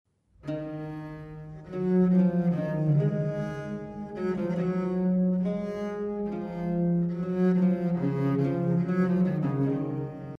• Flautas
• Vihuela de arco
• Vihuela de péñola
• Órgano
Piezas profanas
(vers. inst.)